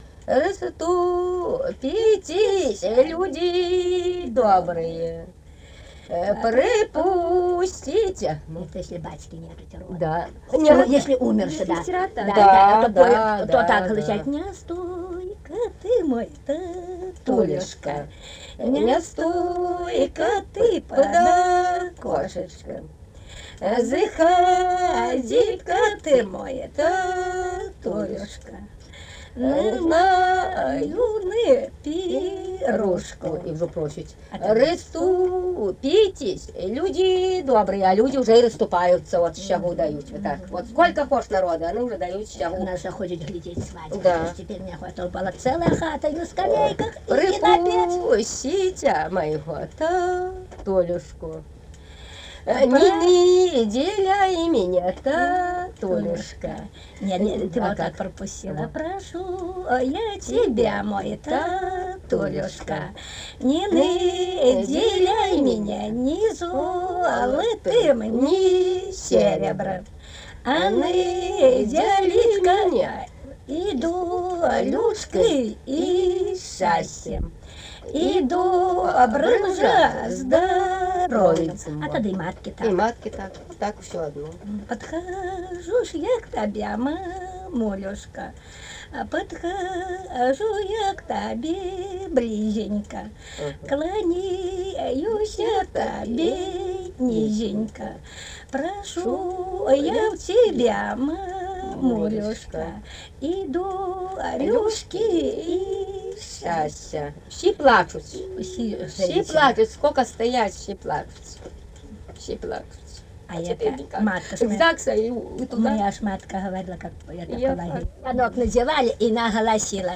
В экспедициях 80-90-х годов XX века гнесинскими фольклористами записаны десятки сиротских песен и плачей, а также многочисленные беседы об обряде.
Цикл свадебных причитаний невесты-сироты
из д. Будницы Велижского р-на Смоленской обл.